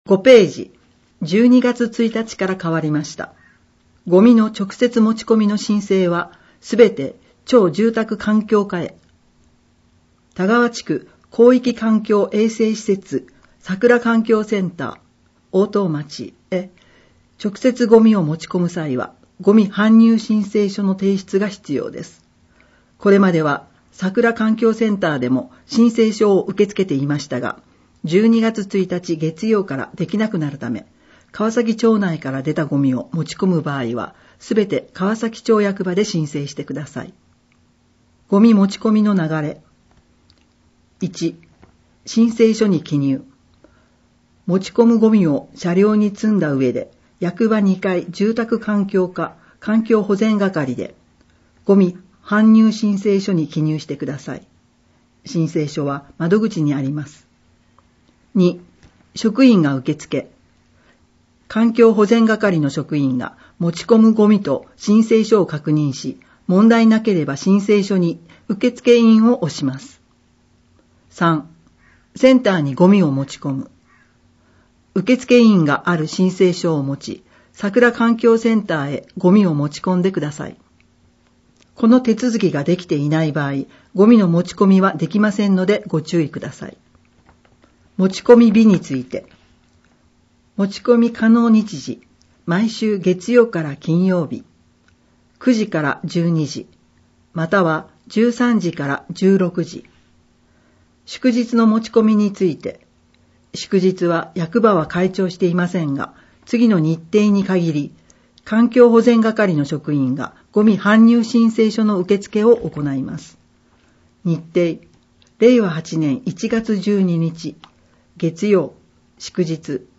『広報かわさき』を音訳している川崎町朗読ボランティア「ひまわり」の作成した音声データを掲載しています。